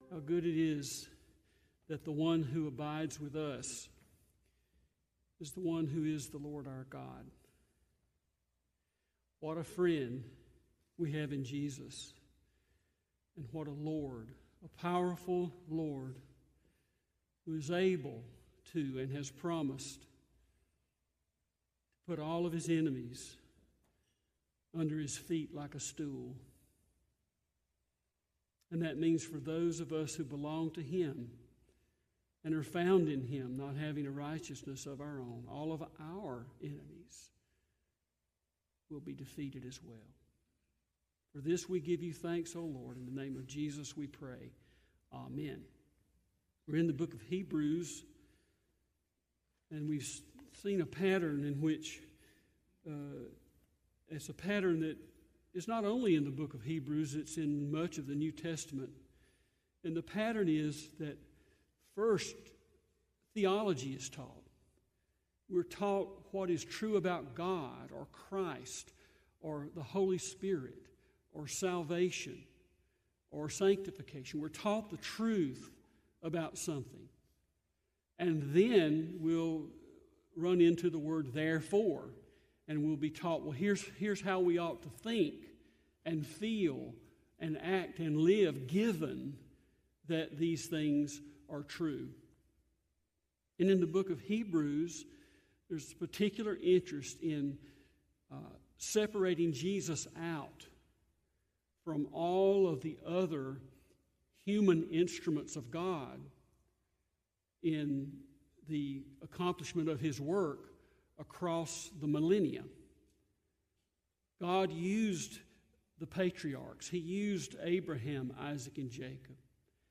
Sermons | Smoke Rise Baptist Church